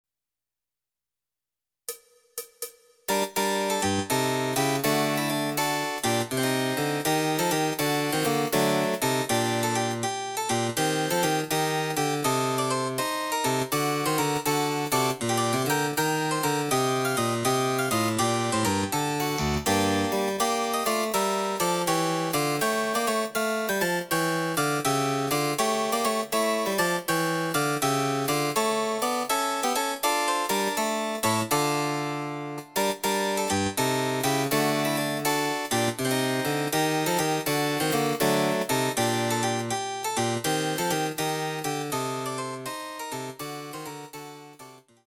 その他の伴奏
第４楽章　非常に遅い
Electoric Harpsichord